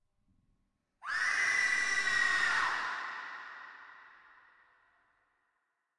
怪物恐怖尖叫声
描述：怪物恐怖尖叫声。
标签： 怪物 糊里糊涂的 尖叫声
声道立体声